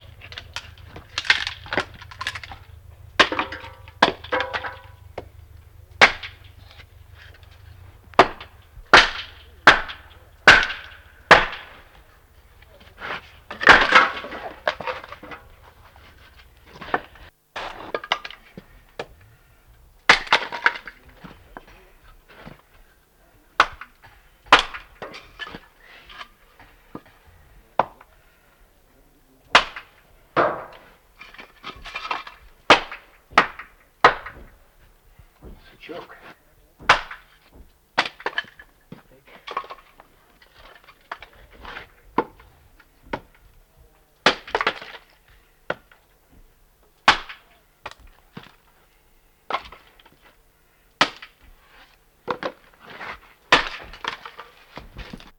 Звук рубки дров на природе